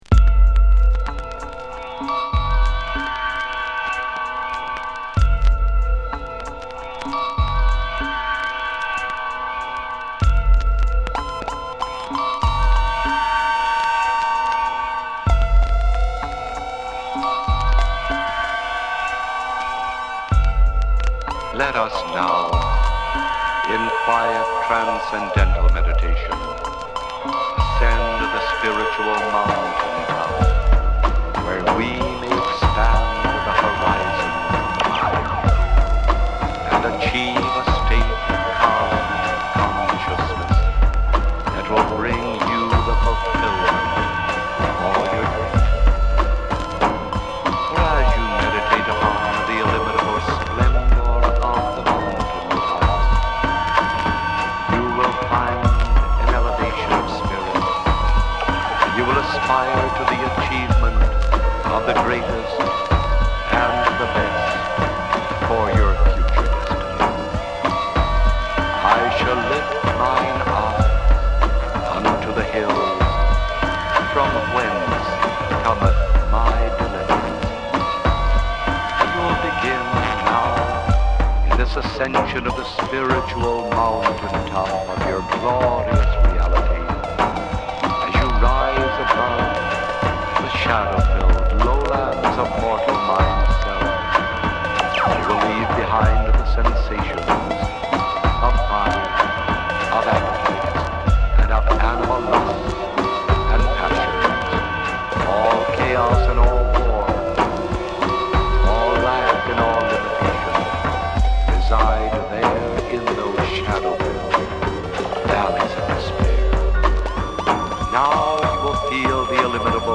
まるで宇宙空間に放たれたような トリッピー・ビーツ